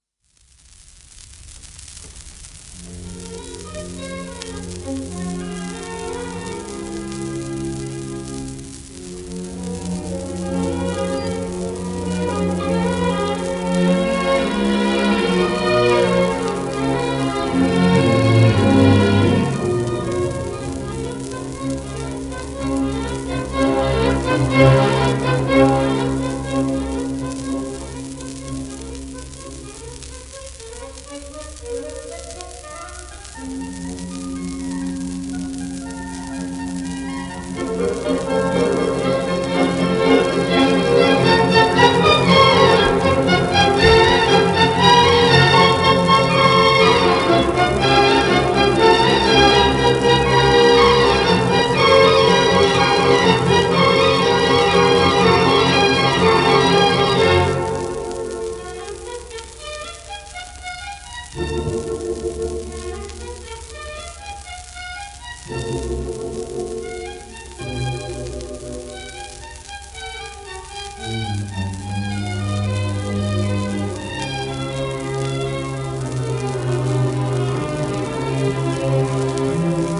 1936年録音
シェルマン アートワークスのSPレコード